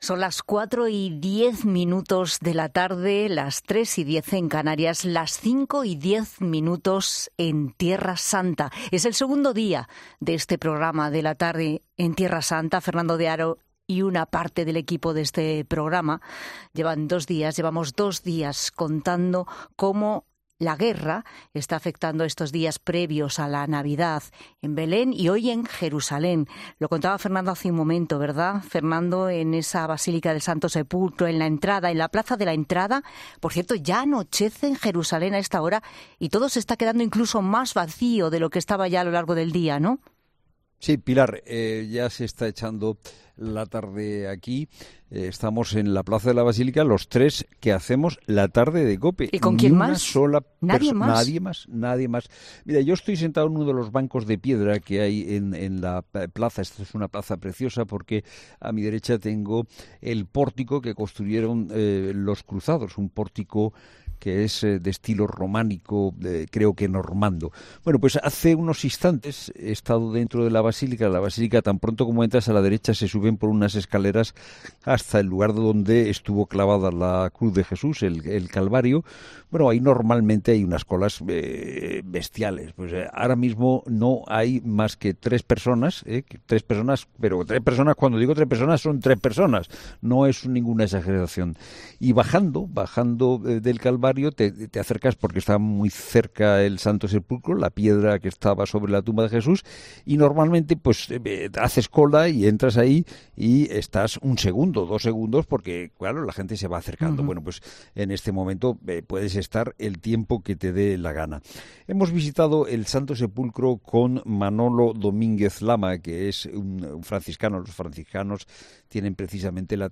¿Cómo se va a vivir esta Navidad en Tierra Santa? El equipo de 'La Tarde' lo cuenta desde Jerusalén